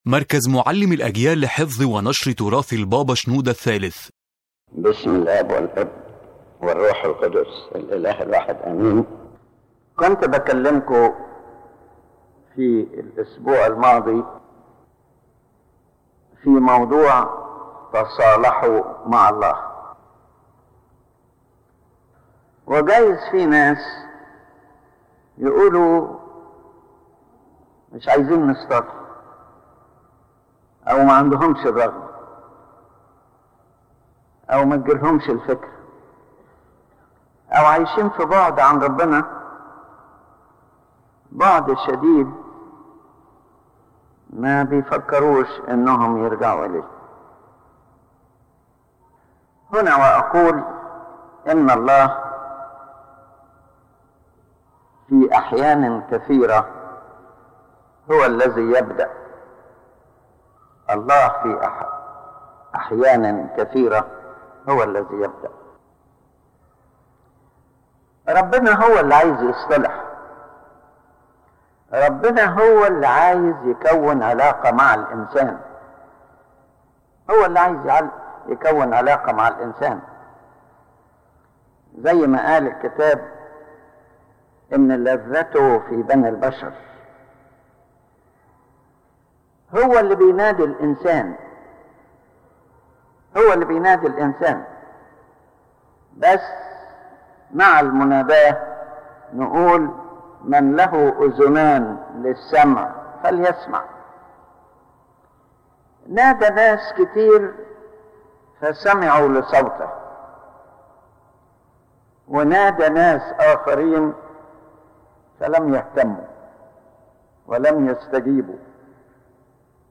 ⬇ تحميل المحاضرة الفكرة الأساسية تدور المحاضرة حول حقيقة روحية عميقة، وهي أن الله في محبته هو الذي يبدأ دائمًا بالعلاقة مع الإنسان.